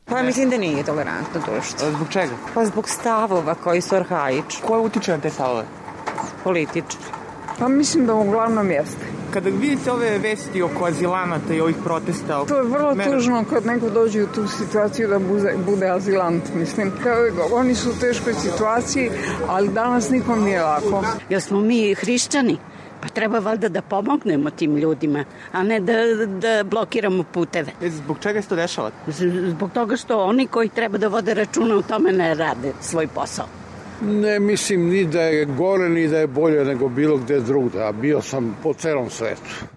Pitali smo Beograđane da li je društvo u Srbiji tolerantno: